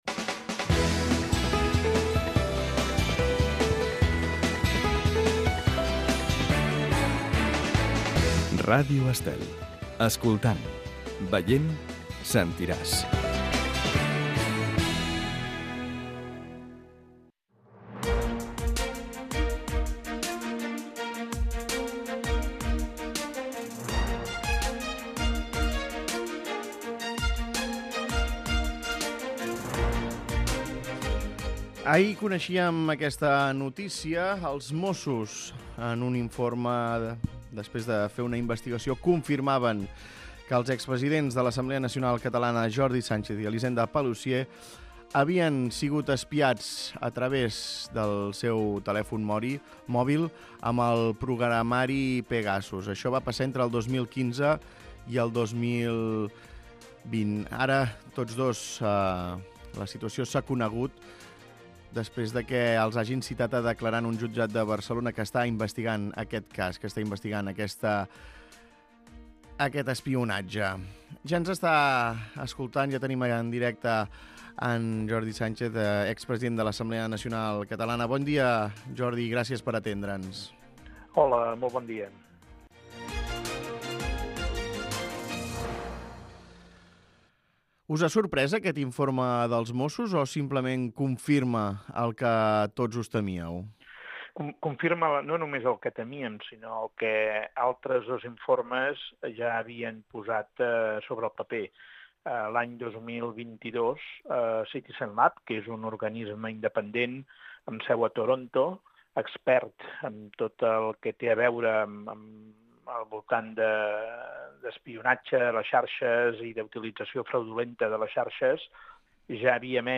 Escolta l'entrevista a Jordi Sànchez, expresident de l'ANC